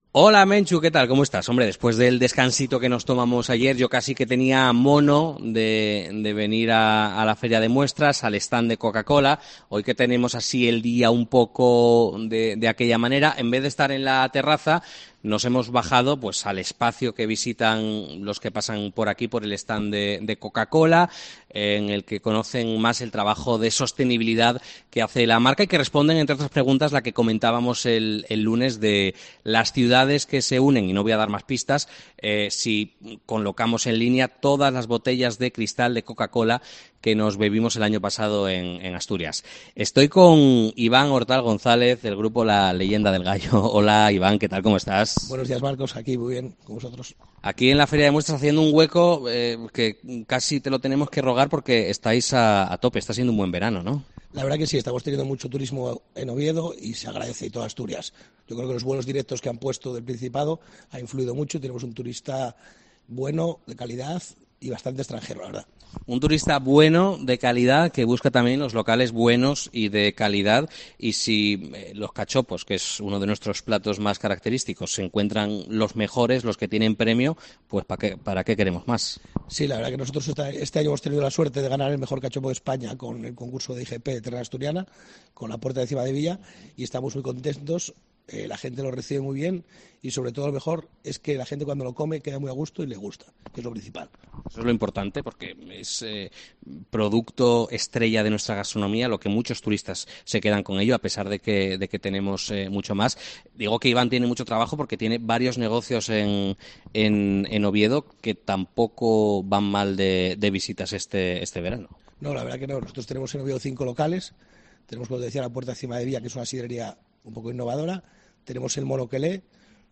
Ha estado en el especial que COPE Asturias emite desde el stand de Coca-Cola en el Recinto Ferial Luis Adaro, con motivo de la celebración de la Feria de Muestras de Asturias
FIDMA 2023: entrevista